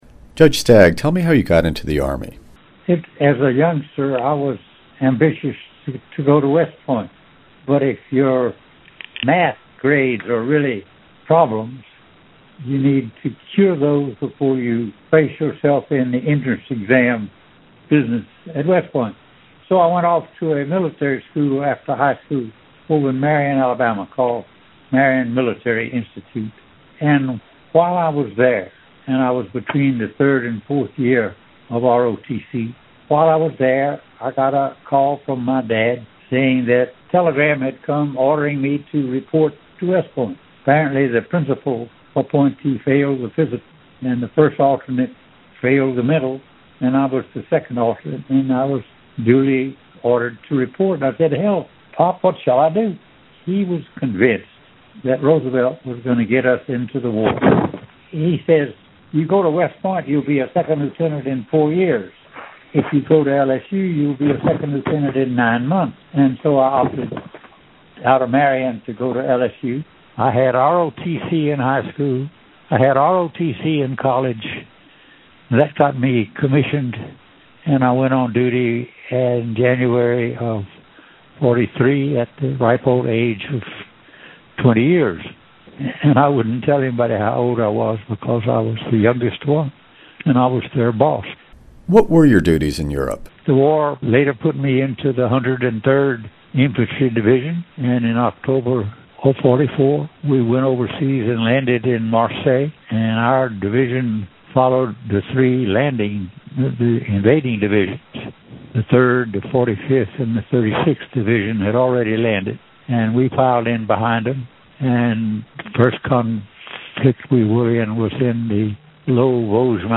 Judge Tom Stagg Audio Interview
stagg-audio-interview.mp3